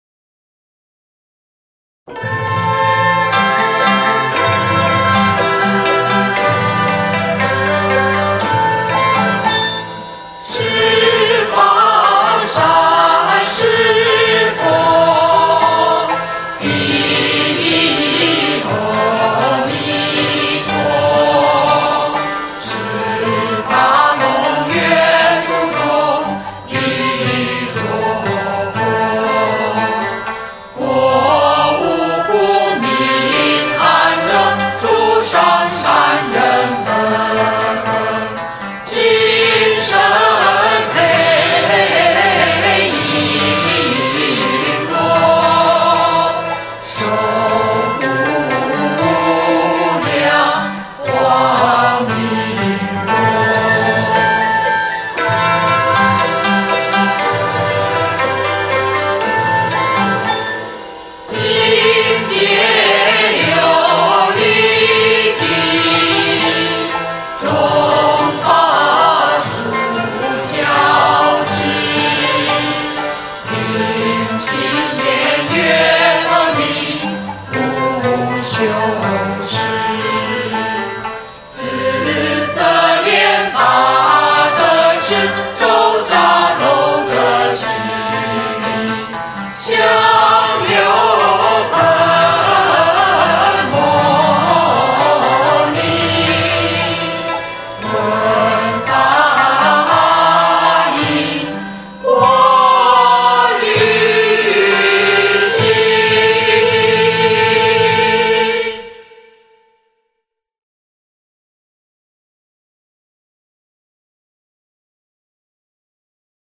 （十二）佛歌
李炳南編詞/簡子愛作曲